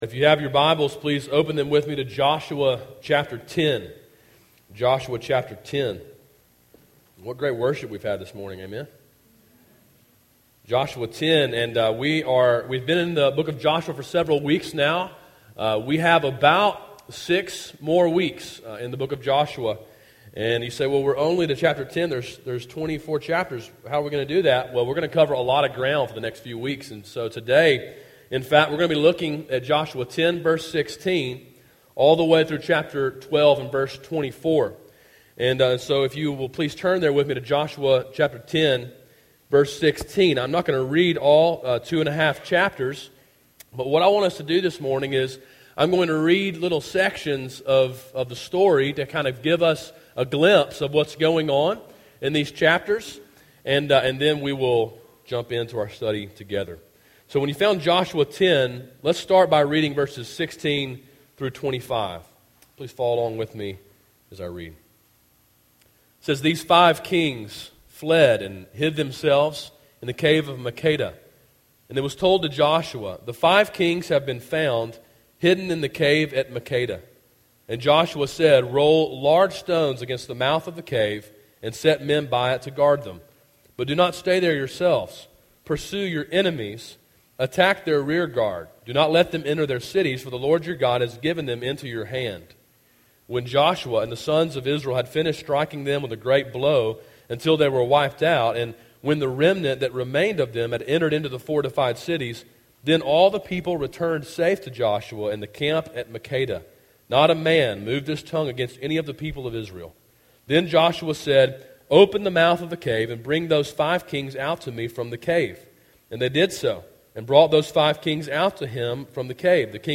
Sermon Audio: “Enemies” (Joshua 10:16-12:24)
A sermon in a series on the book of Joshua.